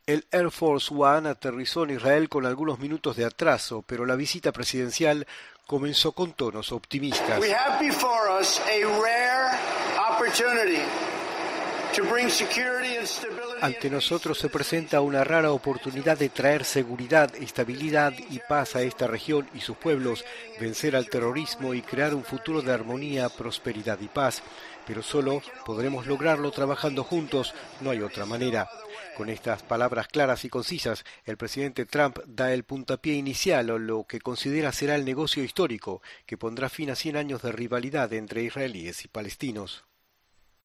"Estamos ante una poco común oportunidad para traer la seguridad y la estabilidad a la región. Para crear armonía, prosperidad y paz", señaló Trump, durante la ceremonia de bienvenida a su llegada al aeropuerto de Ben Gurión, para una visita de 28 horas a Jerusalén y Belén.